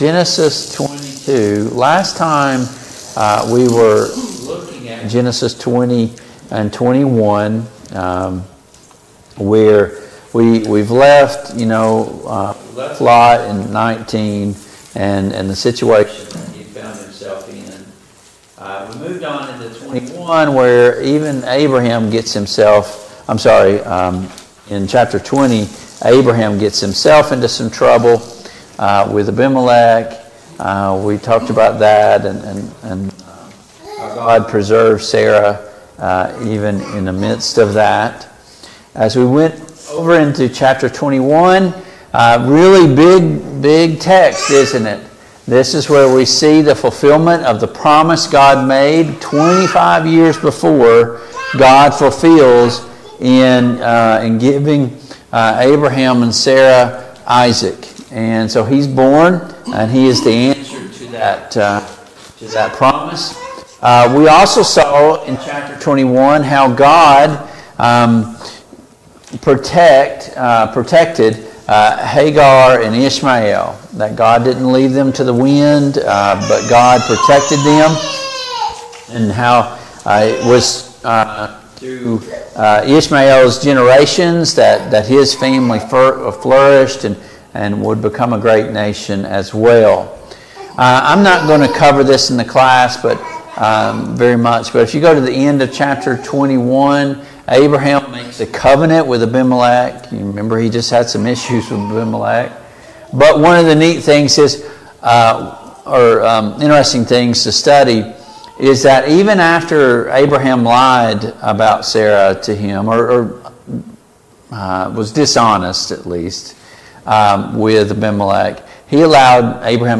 Service Type: Family Bible Hour Topics: Abraham and Isaac